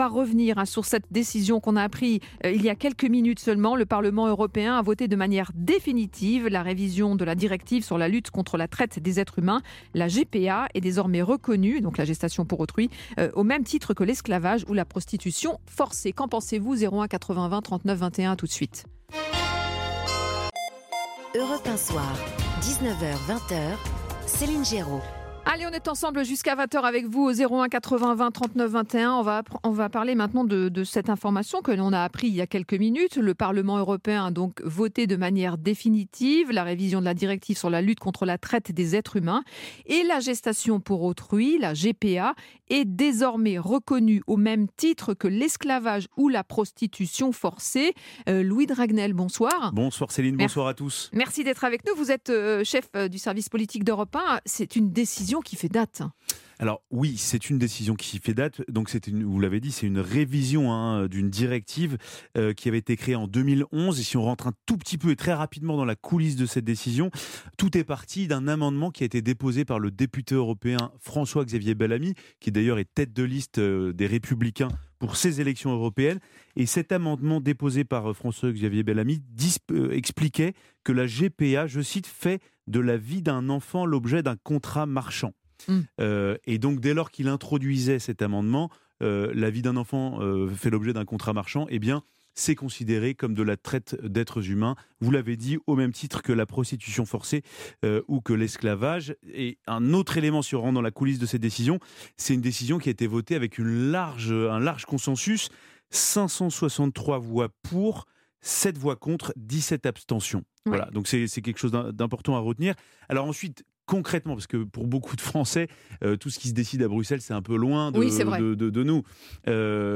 La Présidente du Syndicat de la Famille, Ludovine de La Rochère, était l’invitée de Céline Géraud sur Europe 1.